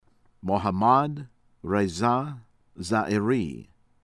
YUSEFIAN, REZA RAY-ZAH   yoo-seh-fee-AHN